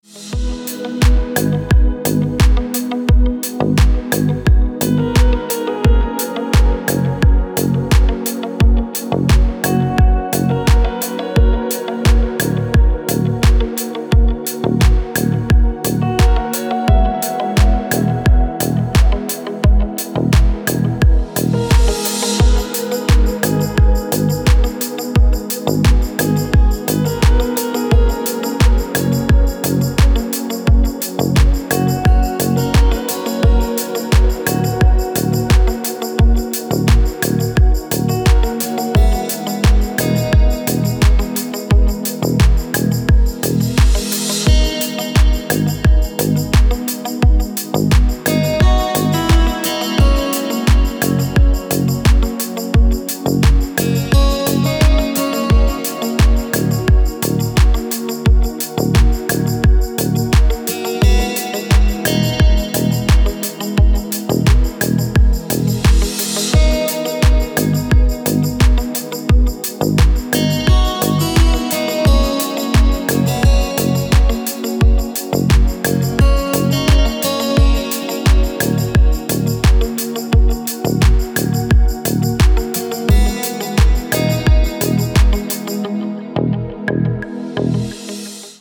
Красивая музыка на входящий
• Категория: Красивые мелодии и рингтоны